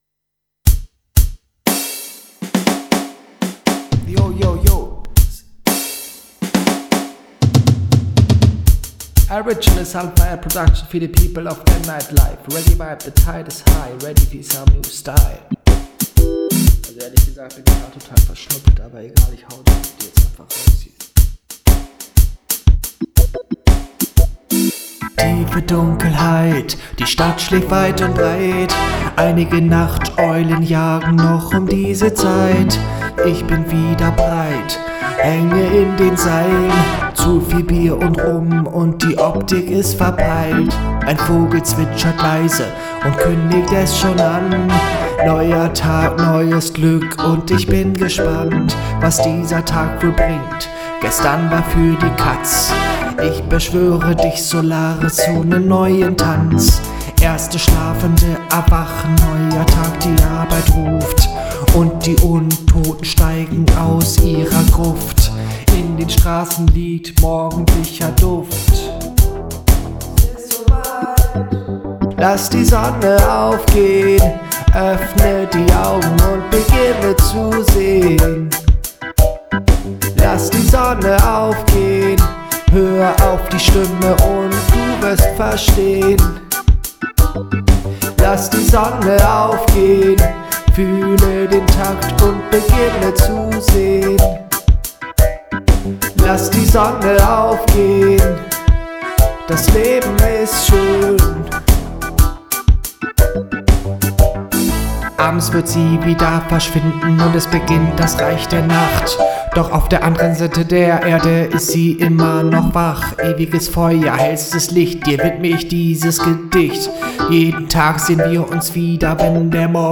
Six fast chanted reggae songs in german language
Style: Reggae, Dancehall
Instruments: Synthesizer (DAW), Vocals